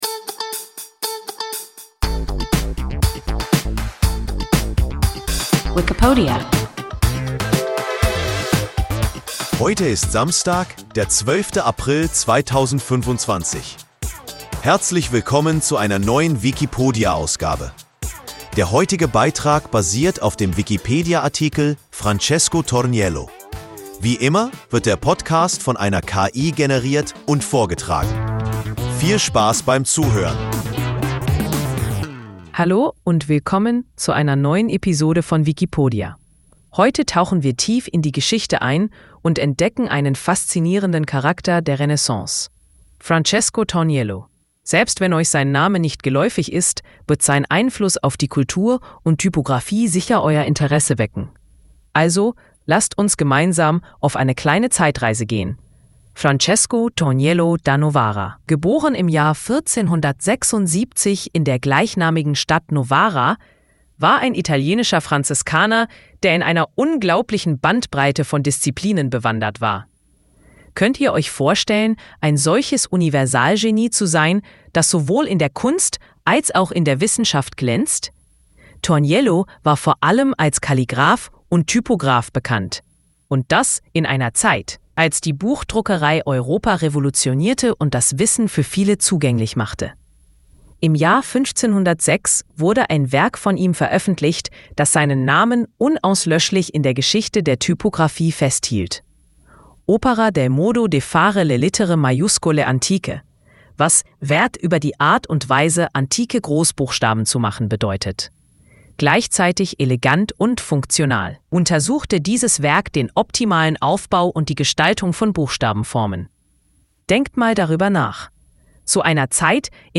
Francesco Torniello – WIKIPODIA – ein KI Podcast